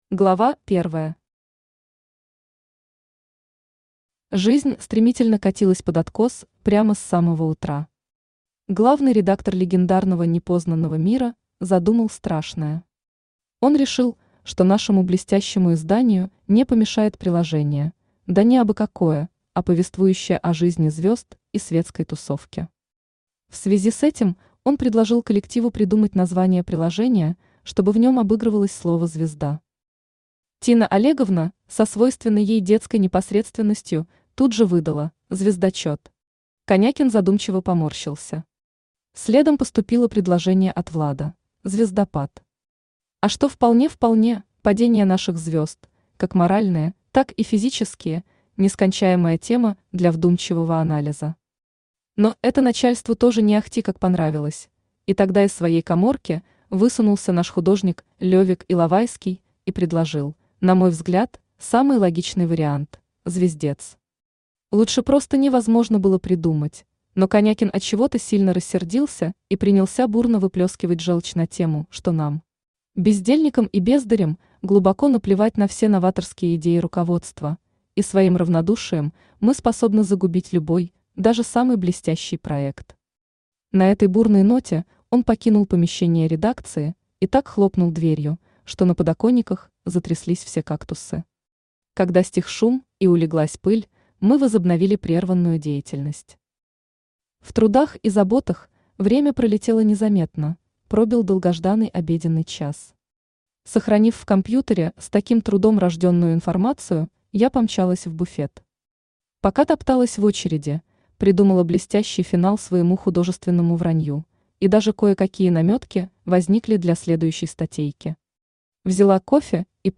Аудиокнига Беседы с мужчиной по вызову